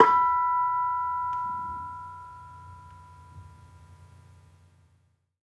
Bonang-B4-f.wav